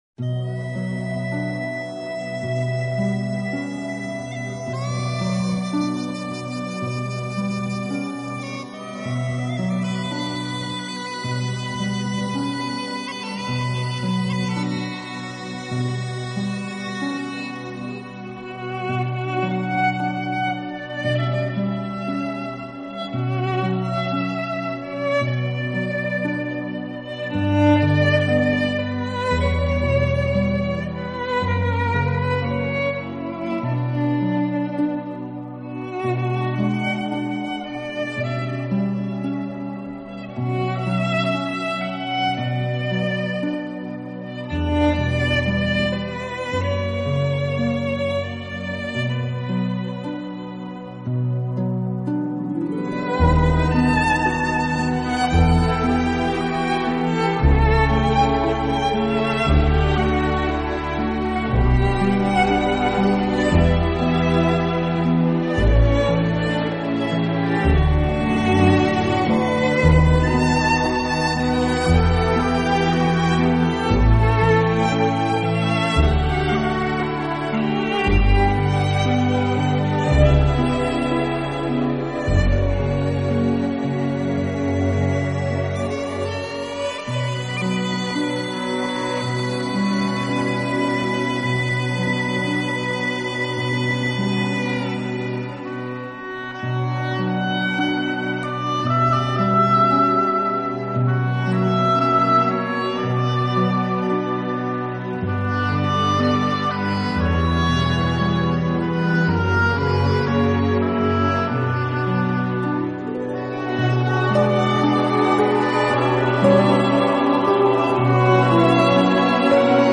音乐类型: New Age / Celtic